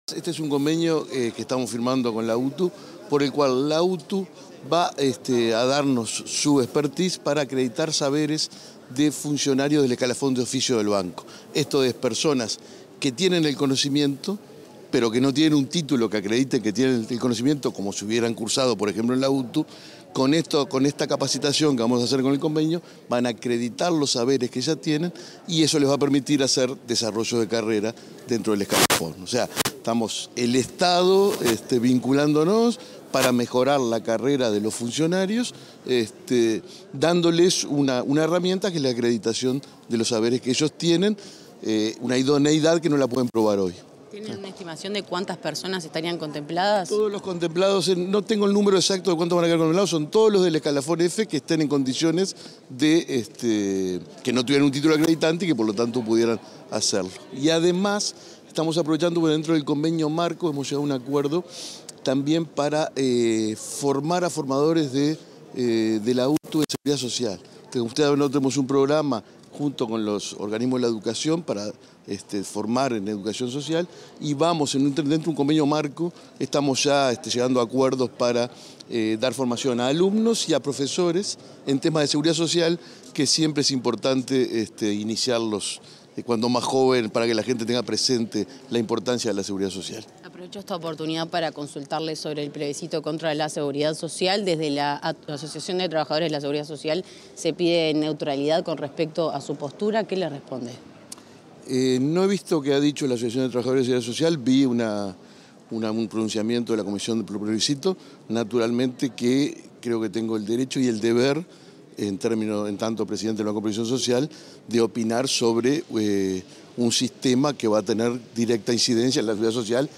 Declaraciones del presidente del BPS, Alfredo Cabrera